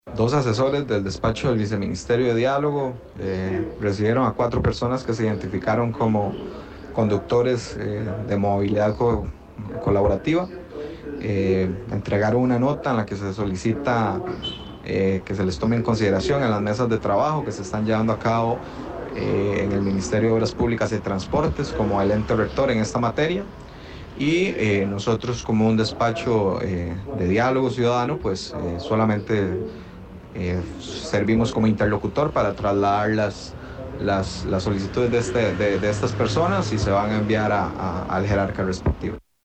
El Viceministro de la Presidencia de Diálogo Social, Juan Alfaro, brindó detalles sobre el encuentro entre funcionarios de Gobierno y representantes de los choferes de Uber.
AUDIO-VICEMINISTRO.mp3